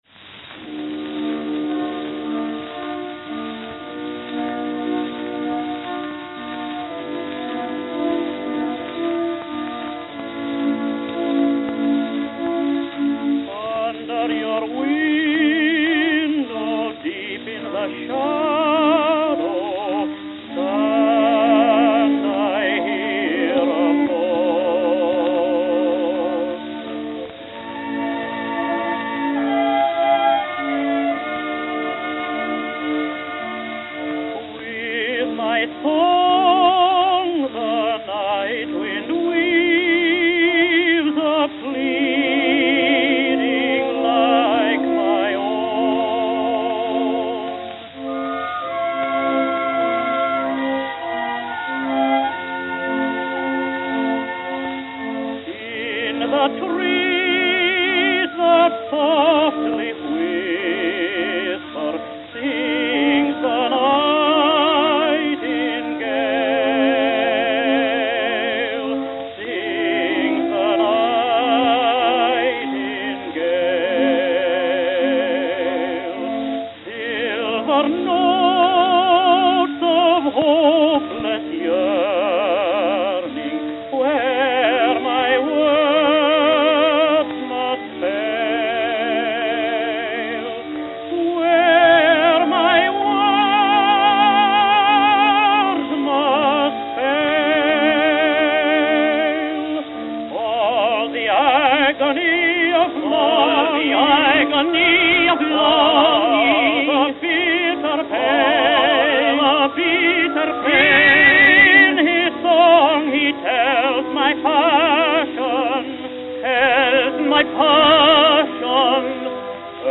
Note: Bad shellac.